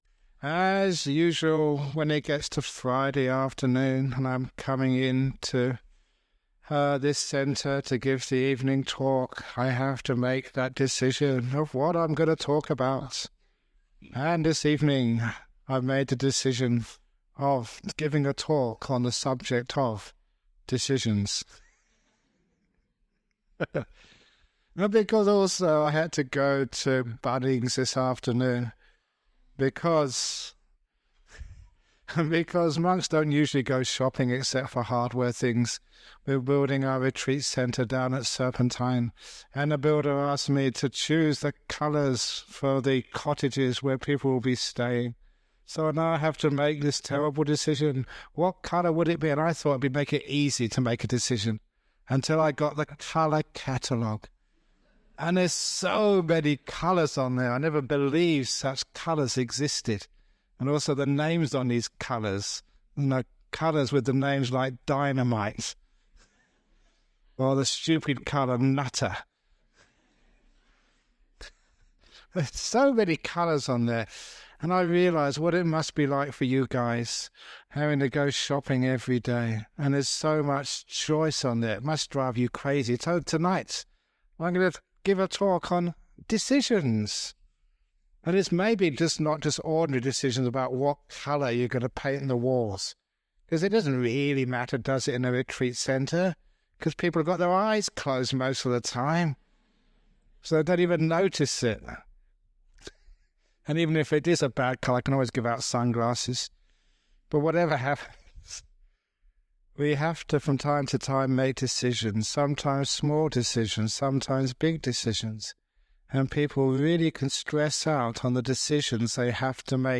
It has now been remastered and published by the Everyday Dhamma Network , and will be of interest to his many fans.